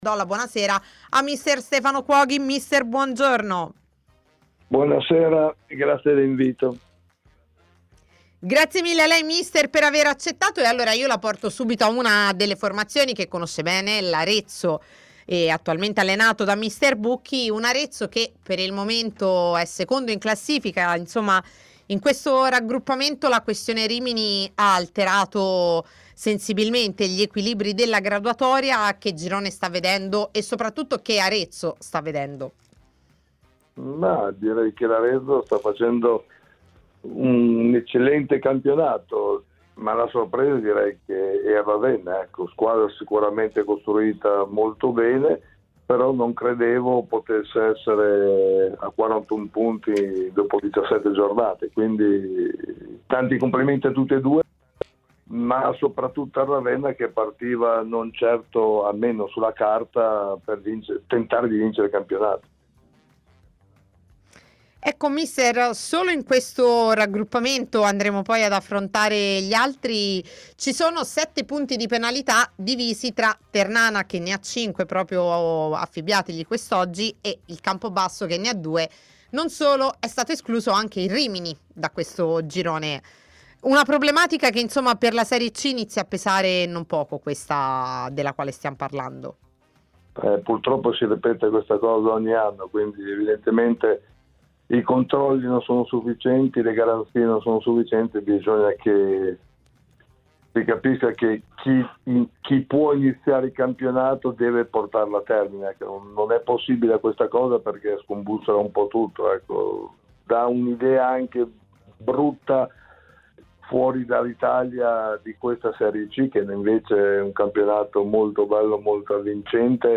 intervistato dai microfoni di TMW Radio